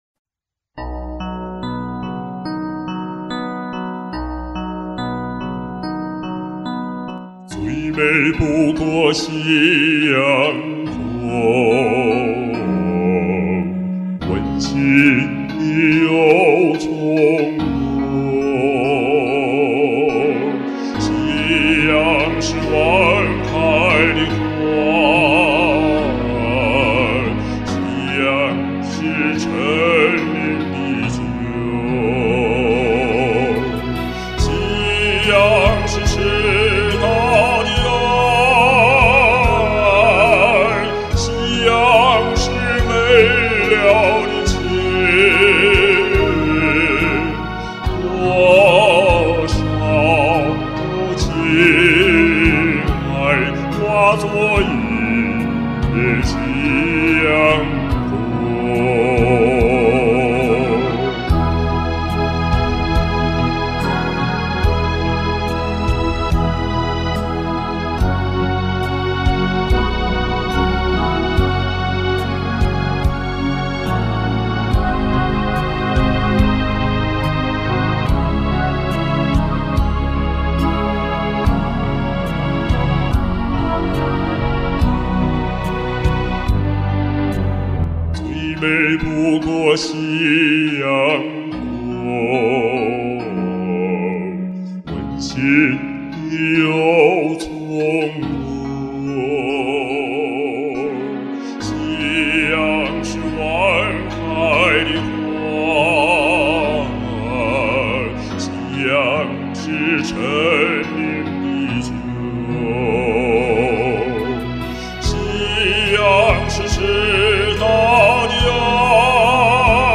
这夜半歌声唱时气虚力竭，听上去也显苍白乏力。